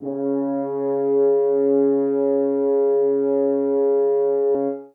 src / assets / instruments / brass / horn-section / samples / C#3_mp.mp3
C#3_mp.mp3